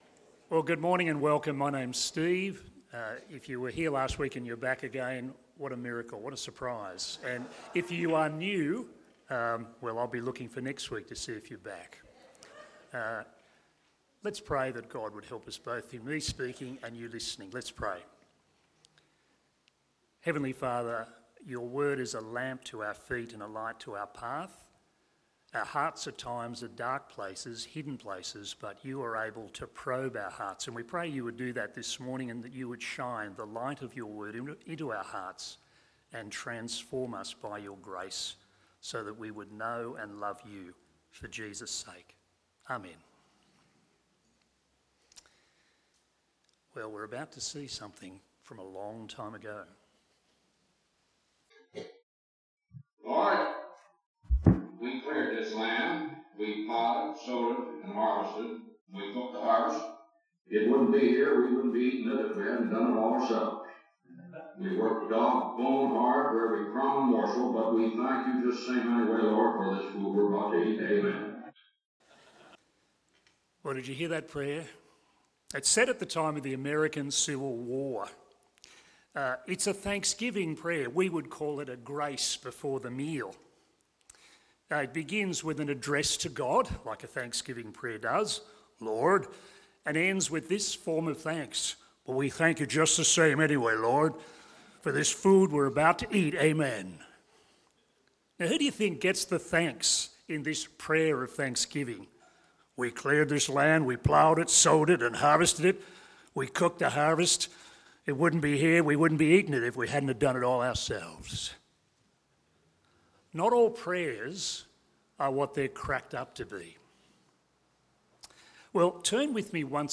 Sermons
Bible Talk on Jonah 2 from the Mission Minded series.